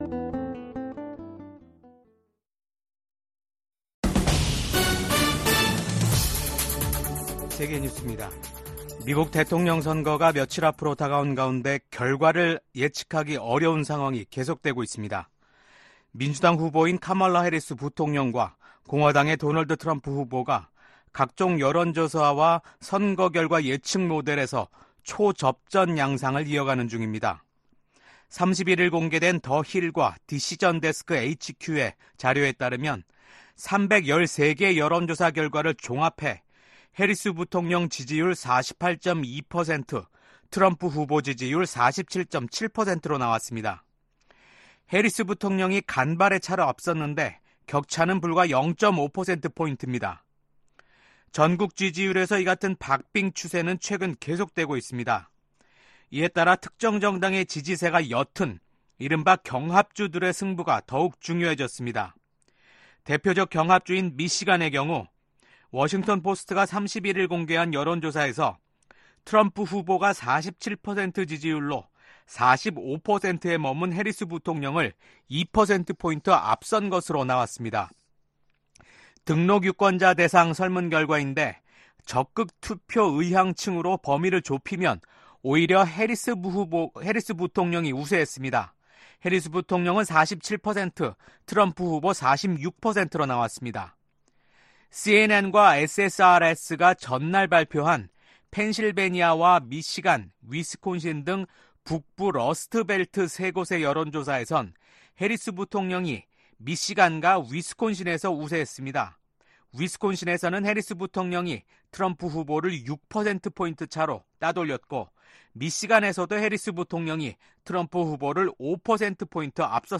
VOA 한국어 아침 뉴스 프로그램 '워싱턴 뉴스 광장' 2024년 11월 1일 방송입니다. 북한이 미국 대선을 닷새 앞두고 동해상으로 대륙간탄도미사일(ICBM)을 발사했습니다. 미국 백악관이 북한의 대륙간탄도미사일(ICBM) 발사를 규탄하고 북한에 불안정한 행동을 중단할 것을 촉구했습니다. 미국과 한국의 국방장관이 북한군의 러시아 파병을 한 목소리로 강력하게 규탄했습니다.